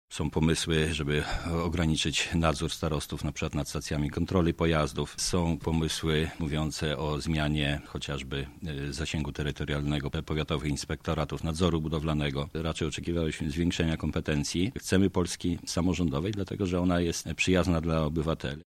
-mówi Paweł Pikula, starosta lubelski.